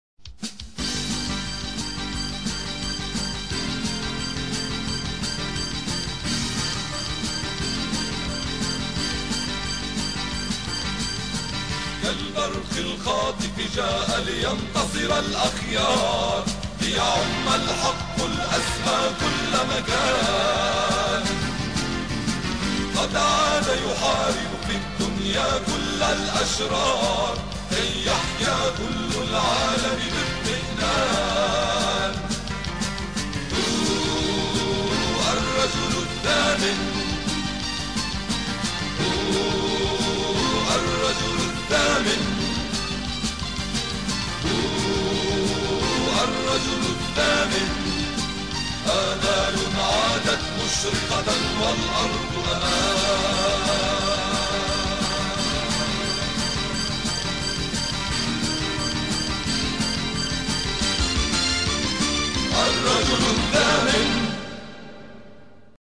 الرجل الثامن - الحلقة 1 مدبلجة